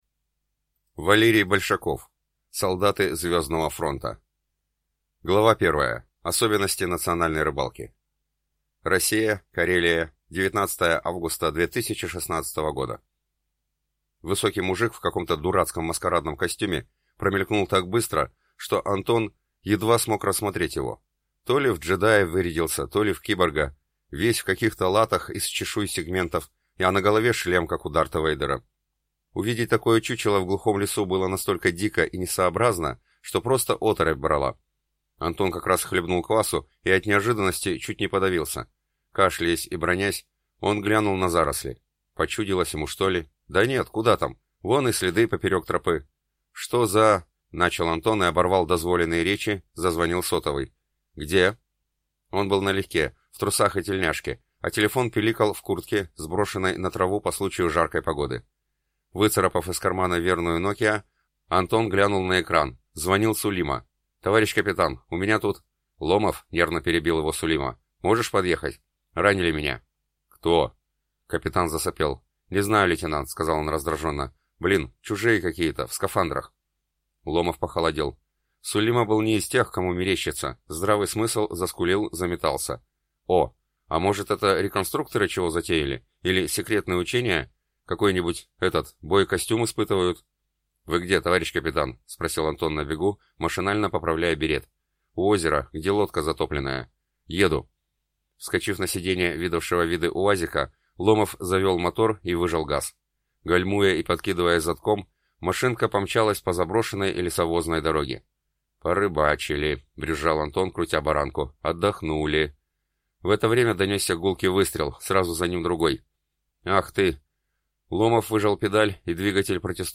Аудиокнига Солдаты звездного фронта | Библиотека аудиокниг